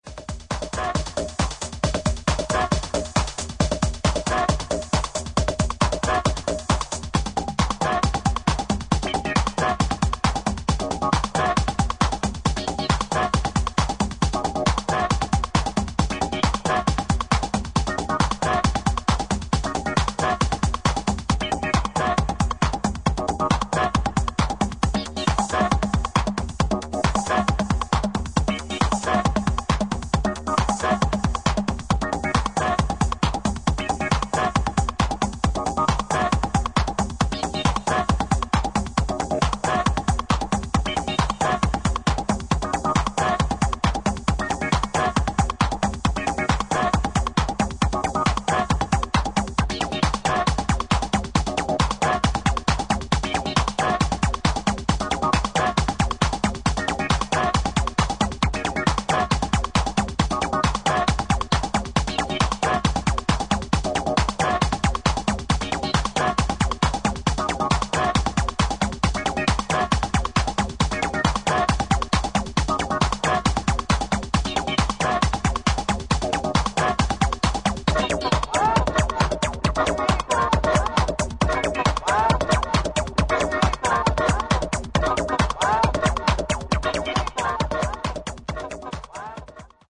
ハードウェアサウンドの面白さを堪能できる一枚です。